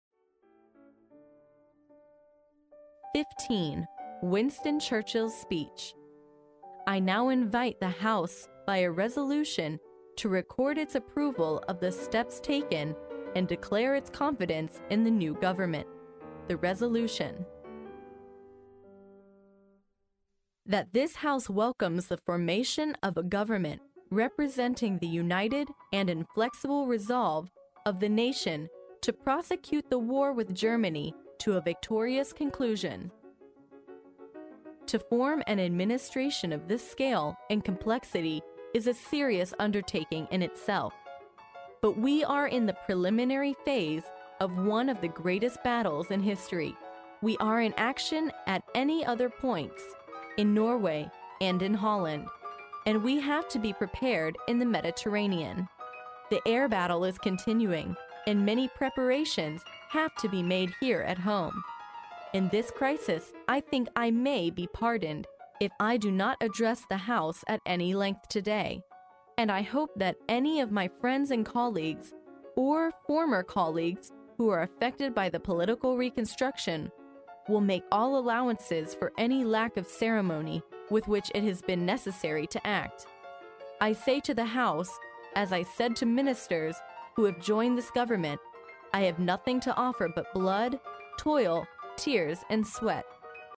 在线英语听力室历史英雄名人演讲 第66期:丘吉尔演讲稿(1)的听力文件下载, 《历史英雄名人演讲》栏目收录了国家领袖、政治人物、商界精英和作家记者艺人在重大场合的演讲，展现了伟人、精英的睿智。